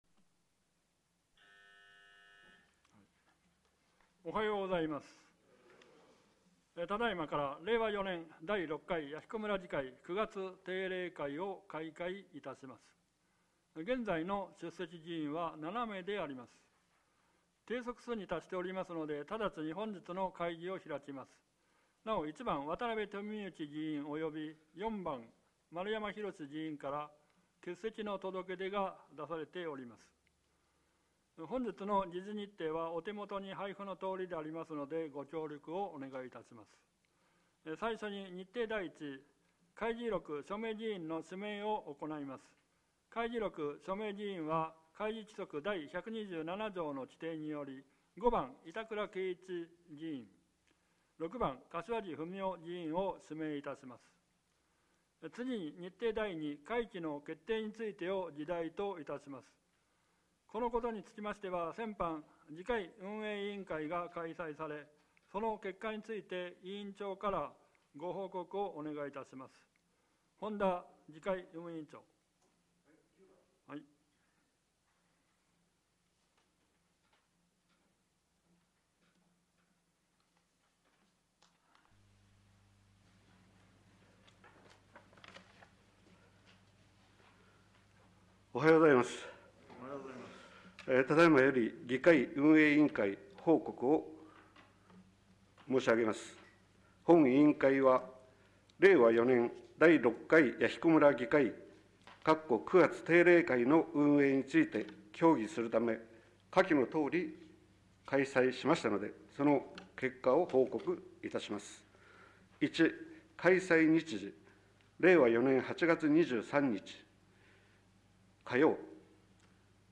本会議